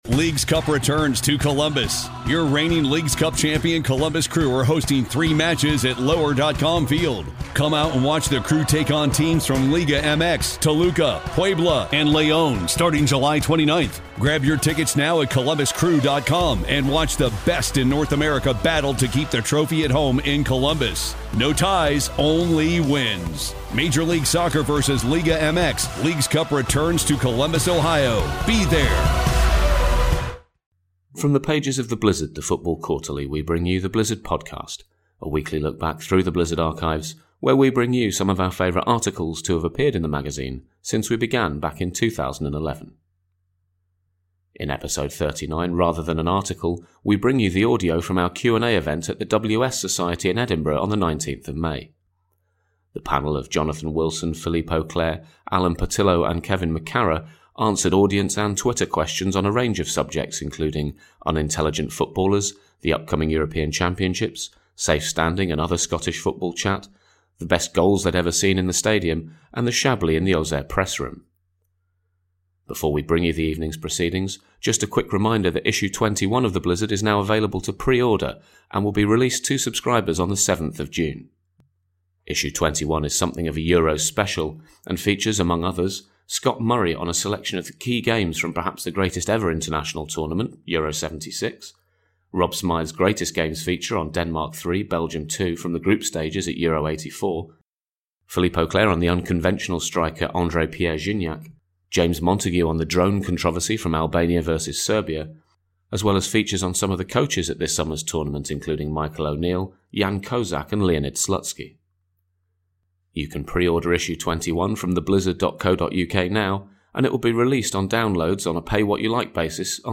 The first half of the Blizzard's live Q&A event held at the WS Society in Edinburgh on 19th May.